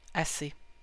s sans, ça,
assez, soixante, six sir
Fr-assez-ca-Montréal.ogg.mp3